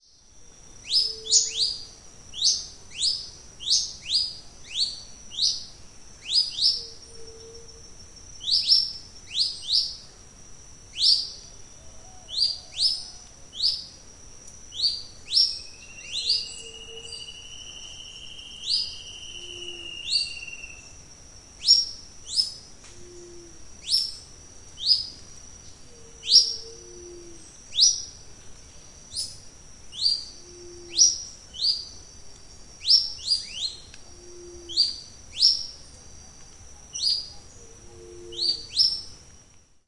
Olivaceous Woodcreeper (Sittasomus griseicapillus)
Se escuchan además:Leptotila verreauxi, Hypoedaleus guttatusy, quizás también,Geotrygon montana
Location or protected area: Parque Nacional Caazapá
Condition: Wild
Certainty: Observed, Recorded vocal
Sittasomus-griseicapillus.mp3